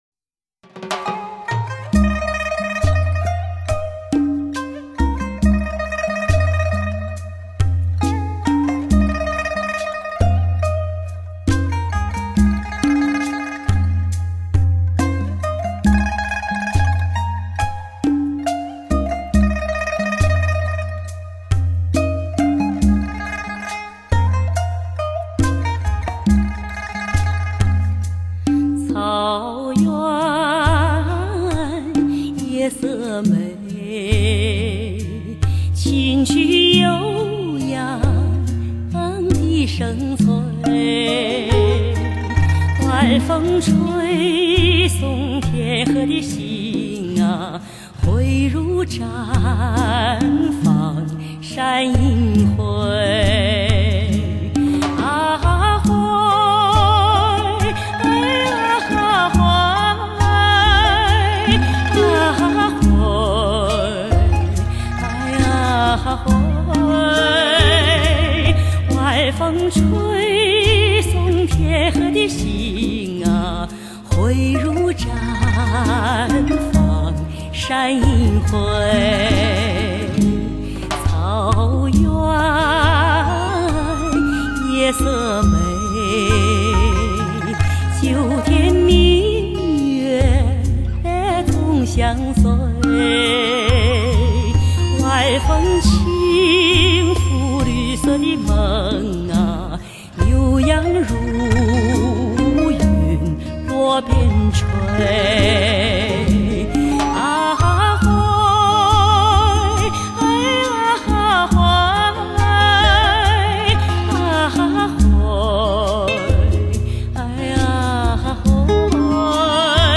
发烧级的音效，令你在驾车途中感受音乐的魔力与震憾！